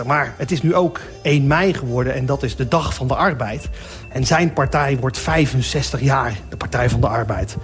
Patrick Lodiers (Vlissingen 25 oktober 1971), tv- en radio-presentator en voormalig voorzitter van BNN (opname 16 mei 2011).